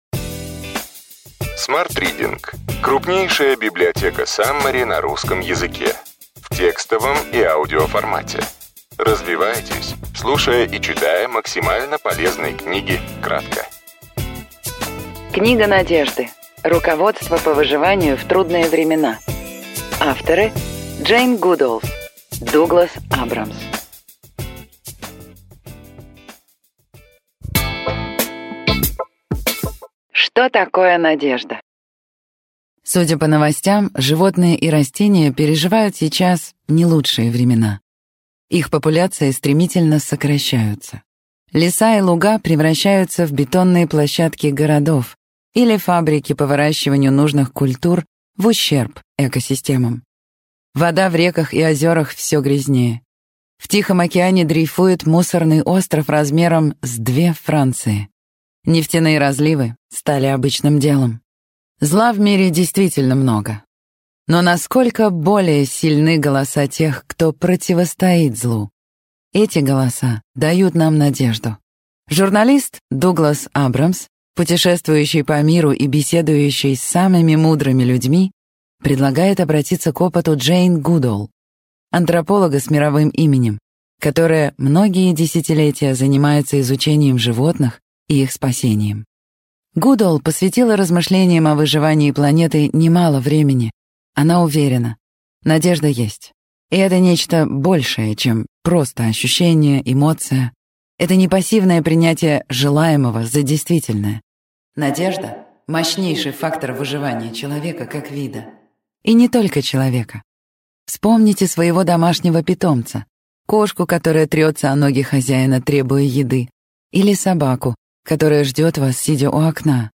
Аудиокнига Ключевые идеи книги: Книга надежды. Руководство по выживанию в трудные времена.